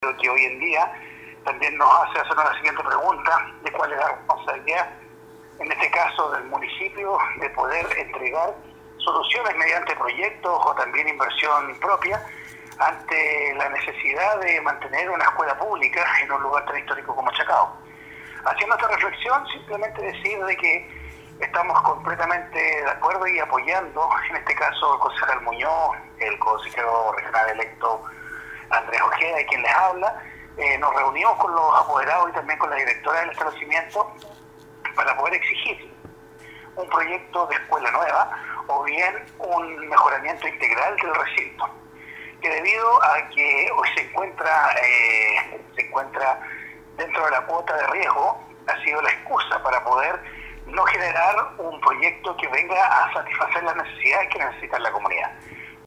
Así lo señaló el concejal Andrés Ibáñez, quien preside esta comisión, y estuvo presente el día miércoles acompañado del concejal Alex Muñoz, conociendo de las dificultades que presenta a la comunidad escolar, la actual infraestructura.
04-CONCEJAL-ANDRES-IBANEZ.mp3